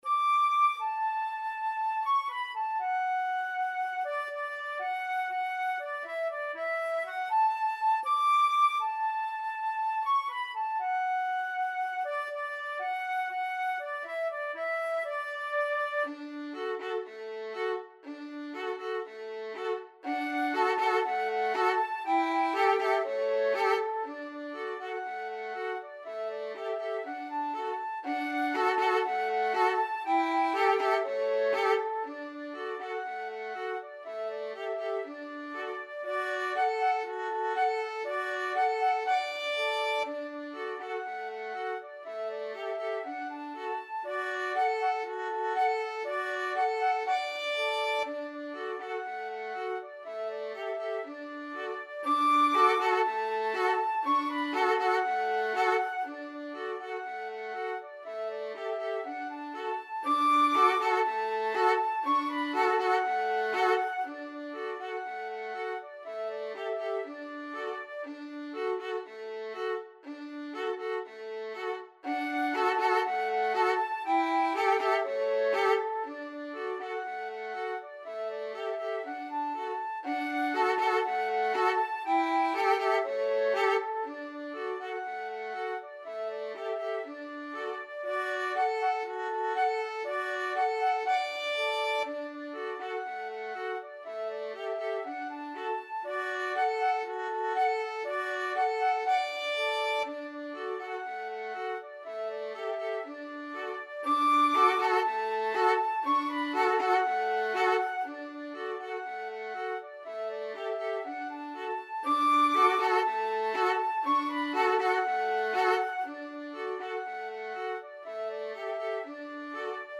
4/4 (View more 4/4 Music)
Fast =c.120